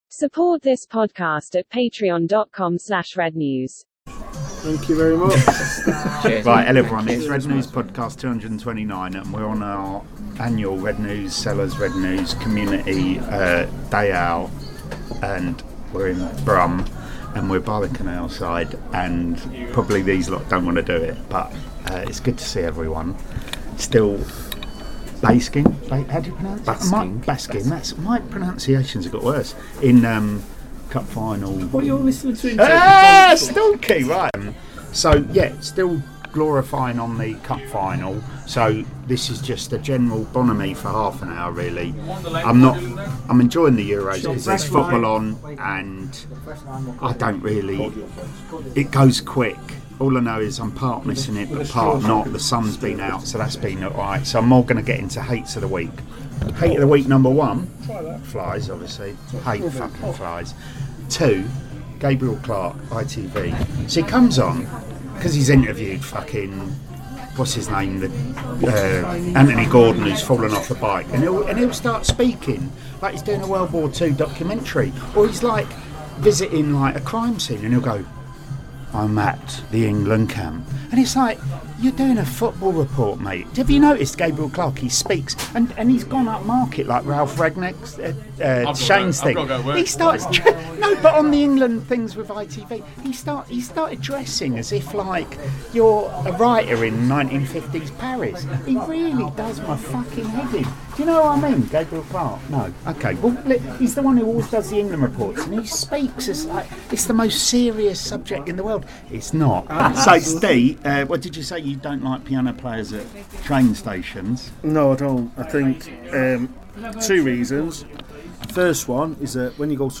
It's the RedNews sellers' annual summer meet up. Join us on a pub crawl for an hour as we talk MUFC, stadium naming rights and cutting toe nails.